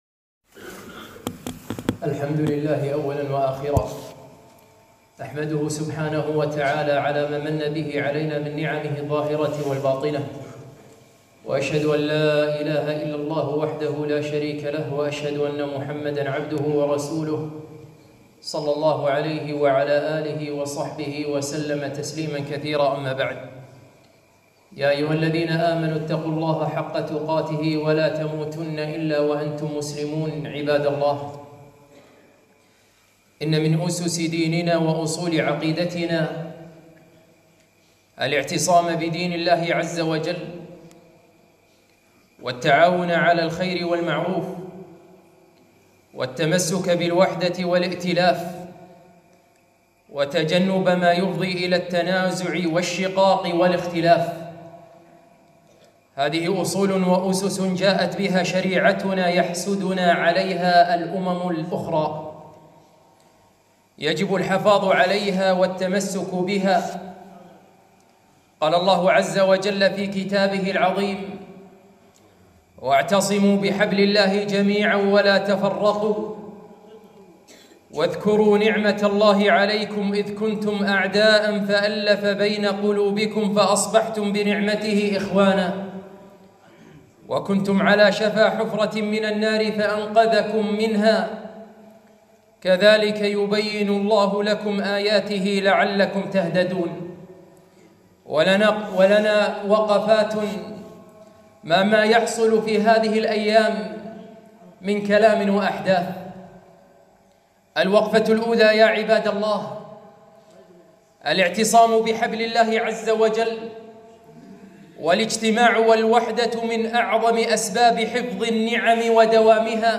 خطبة - وطاننا أمانة